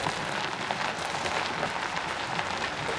rr3-assets/files/.depot/audio/sfx/tyre_surface/tyres_asphalt_crawl.wav
tyres_asphalt_crawl.wav